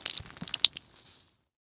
blood.ogg